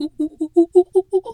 pgs/Assets/Audio/Animal_Impersonations/monkey_2_chatter_11.wav at master
monkey_2_chatter_11.wav